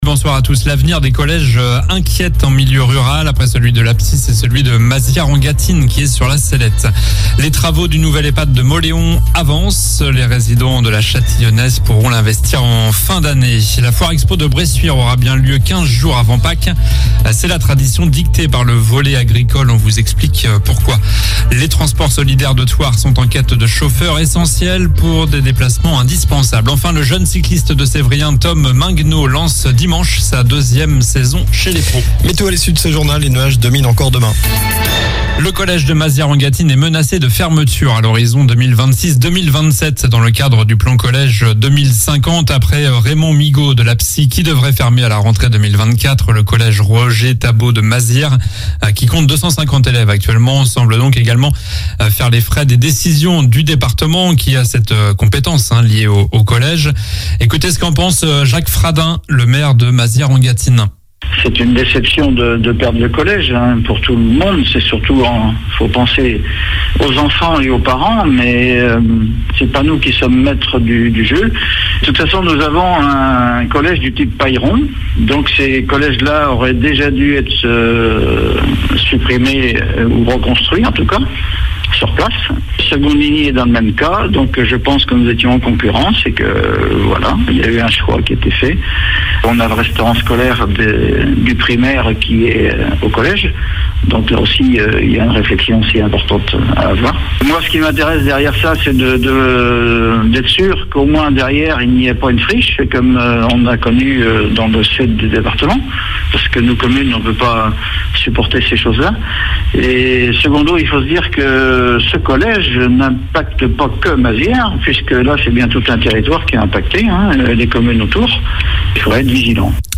Journal du jeudi 26 janvier (soir)